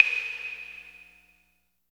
35 CLAVE  -L.wav